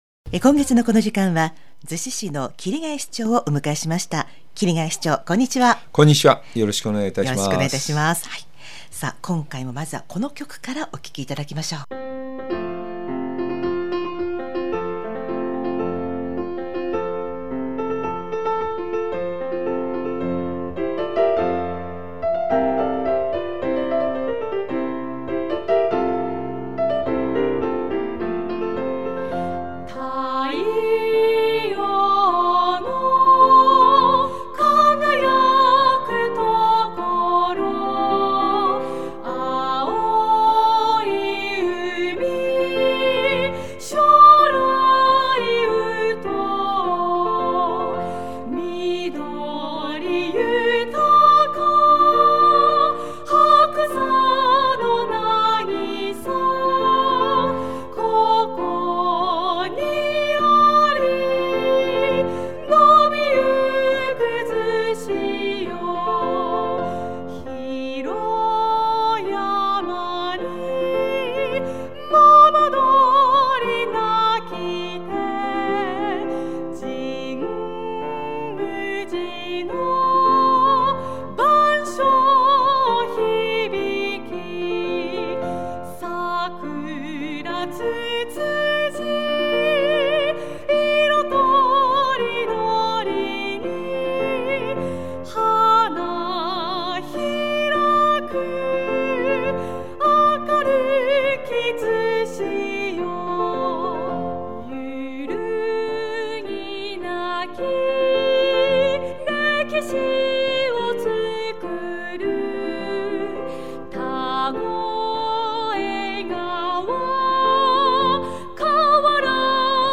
市町長県議インタビュー